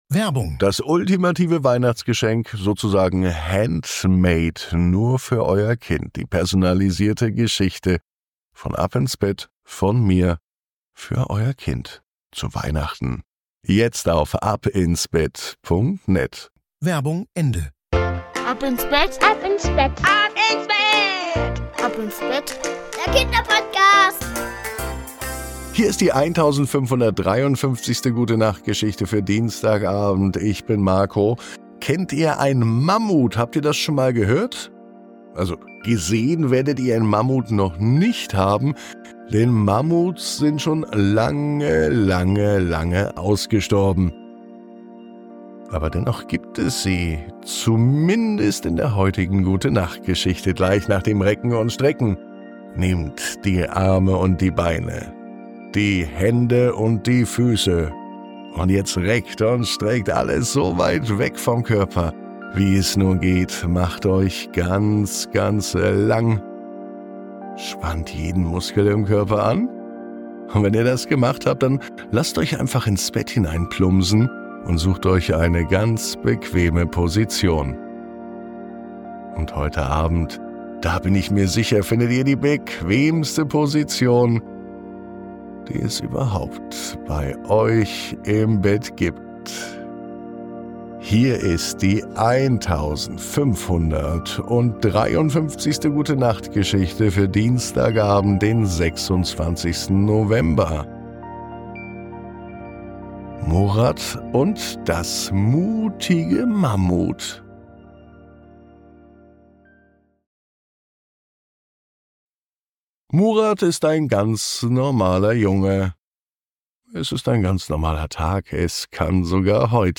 Die Gute Nacht Geschichte für Dienstag